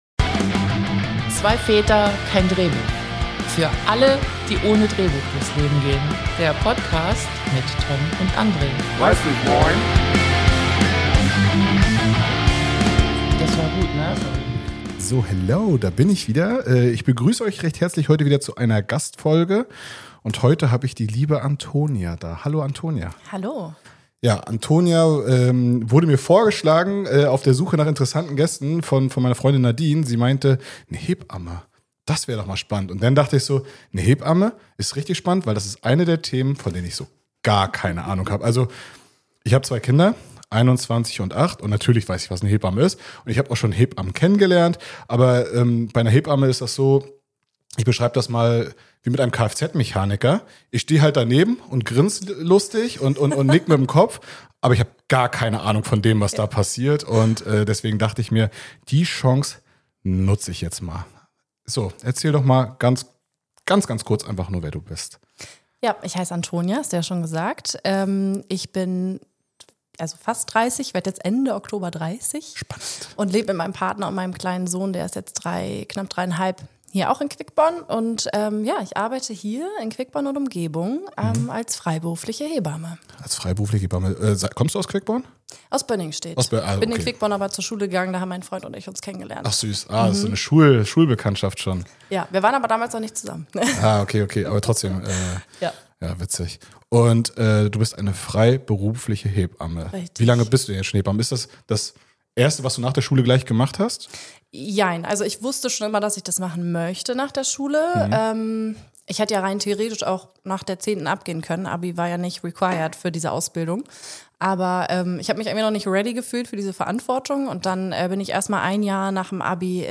Ein warmherziges, ehrliches und zugleich aufrüttelndes Gespräch über einen der wichtigsten, aber oft unterschätzten Berufe der Welt.